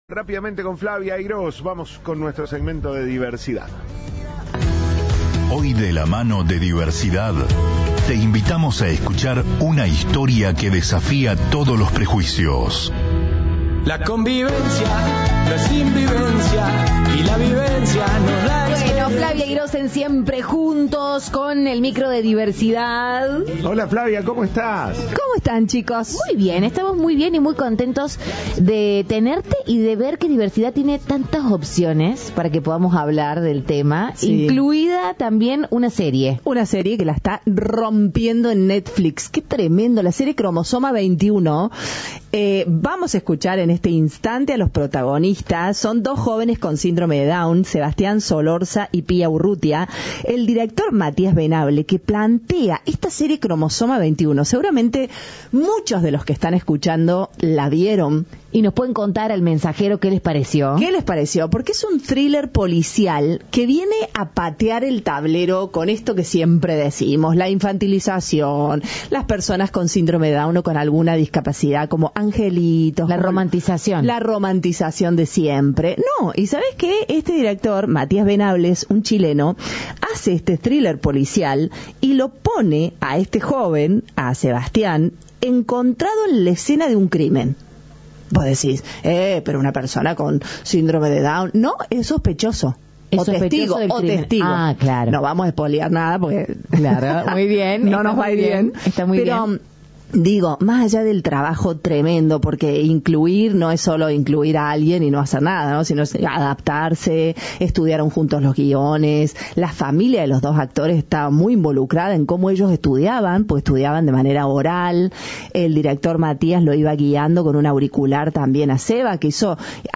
Mano a mano con los protagonistas y el director de la serie "Cromosoma 21"
Entrevista